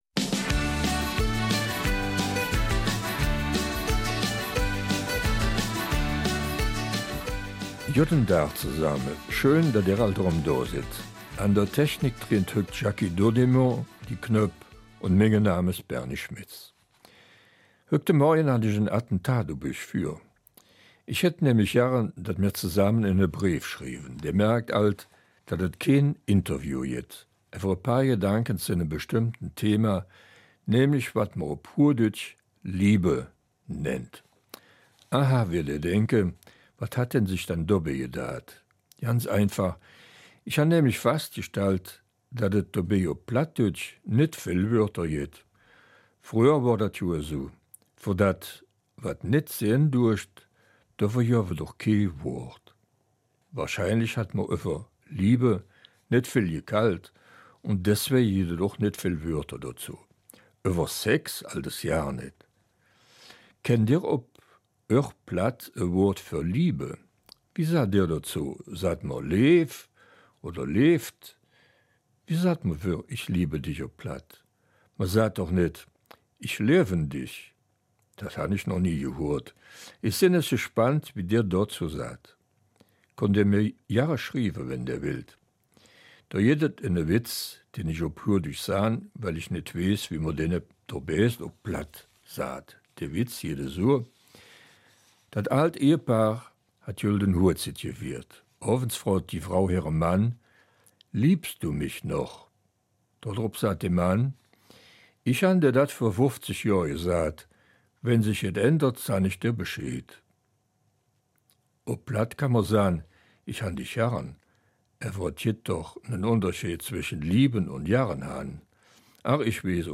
Eifeler Mundart - 19. Oktober